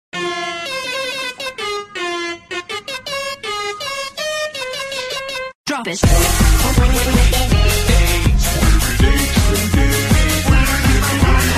twitchhitbox-followdonation-sound-1-hd.mp3